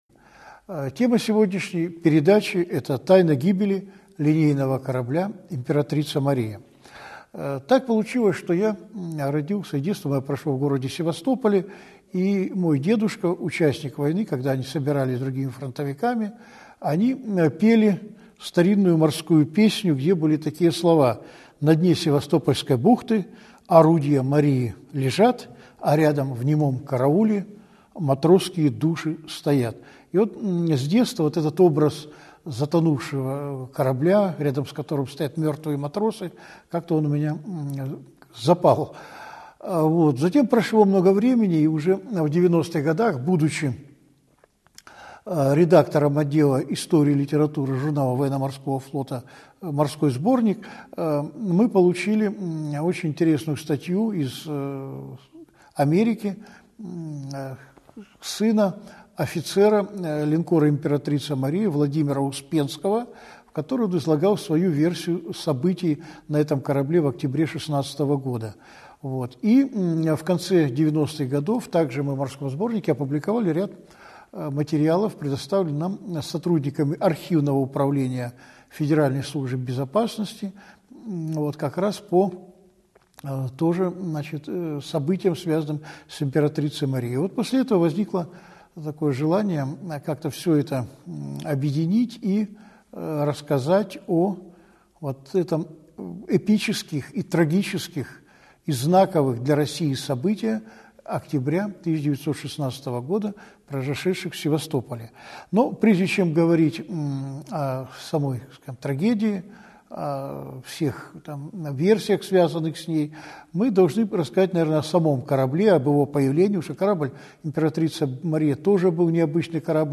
Аудиокнига Тайна гибели линкора «Императрица Мария» | Библиотека аудиокниг